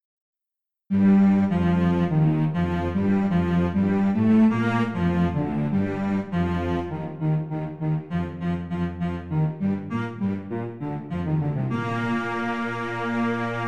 I used the same reverb plugin on both examples.